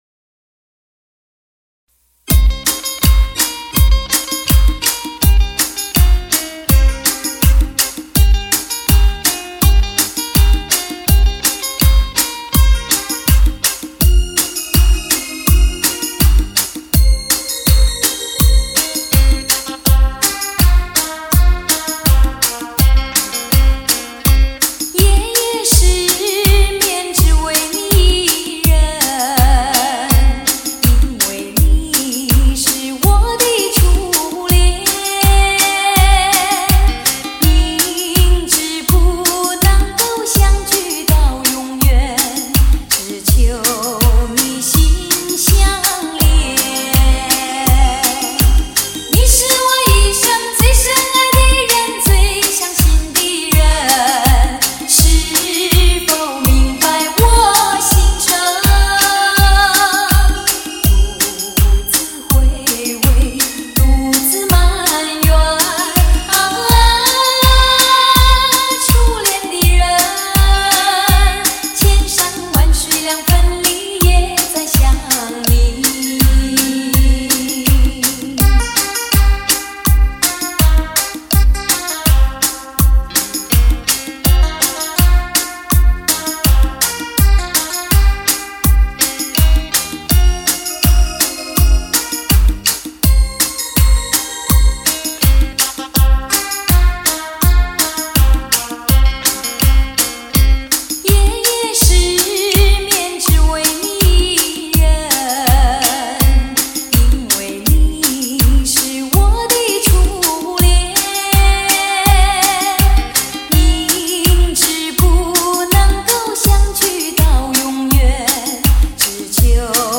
推荐绝版甜歌2016最新创作双电子琴车载情歌